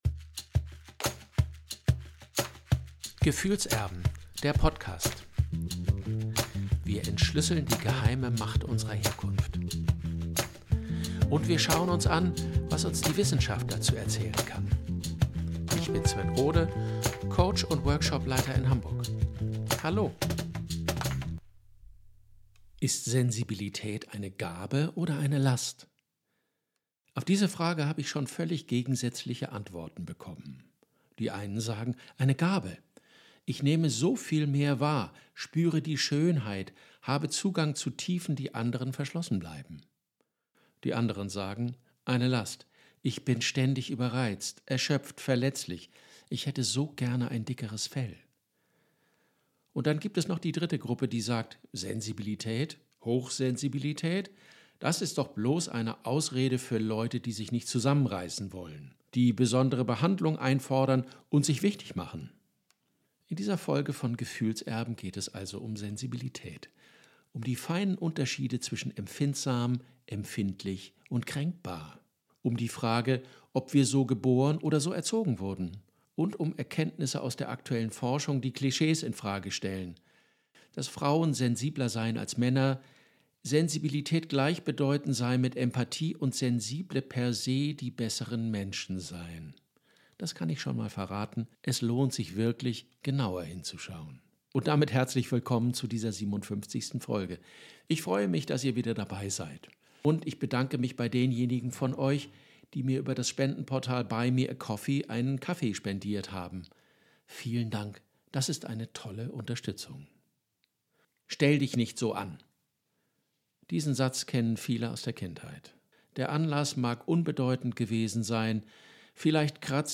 In dieser Folge von Gefühlserben (in der noch ein wenig von einer Erkältung zu hören ist) geht es um Sensibilität als Temperamentsmerkmal – und um die Frage, was sie ist und was nicht. Es geht um wissenschaftliche Erkenntnisse, um frühe Prägungen, um Trauma und um die feinen Unterschiede zwischen wahrnehmungsstark, überreizt und gekränkt. Und es geht um die Spannungen, die entstehen, wenn Menschen mit sehr unterschiedlicher Sensitivität aufeinandertreffen, in Beziehungen, Familien und im gesellschaftlichen Miteinander.